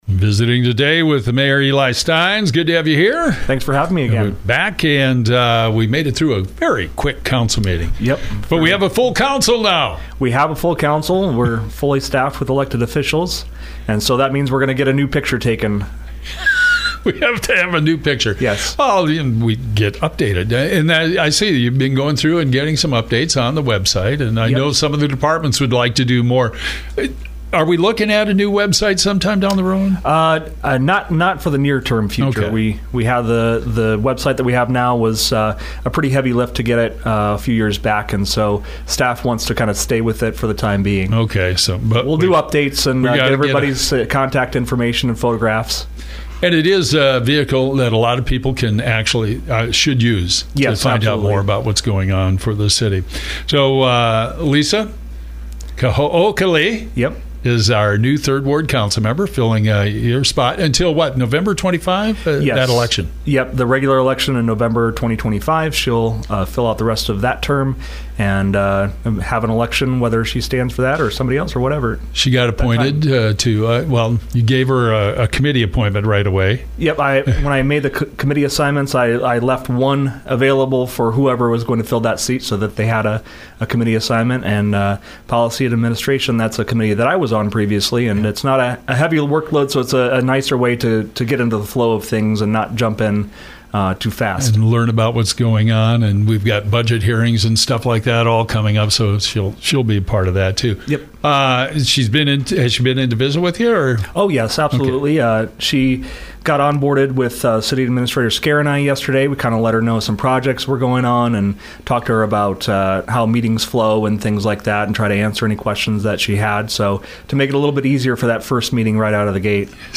Boone Mayor Eli Stines talks about Monday’s Boone City Council Meeting. Stines did deliver the Oath of Office to Lisa Kahookele to fill the unexpired term as the 3rd Ward Councilmember. He also said staff had requested the removal of action to set a referendum for November, for a public vote on a possible recreational complex.